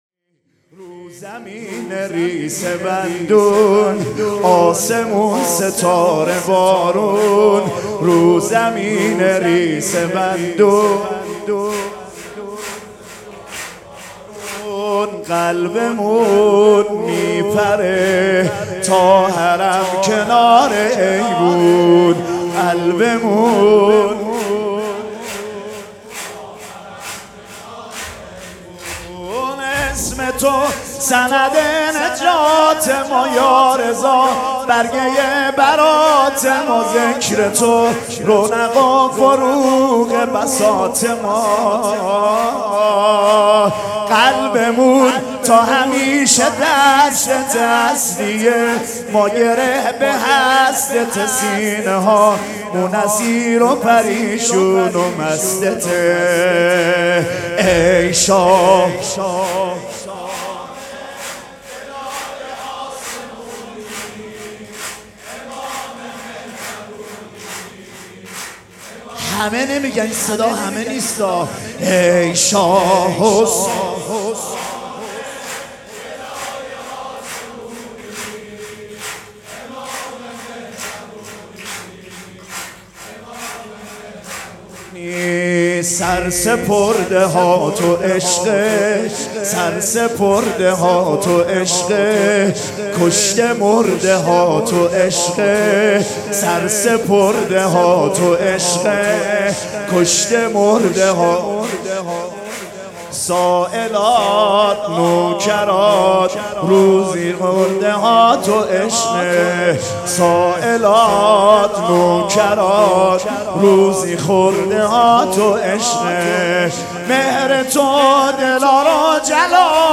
سرود
مداح
ولادت امام رضا علیه السّلام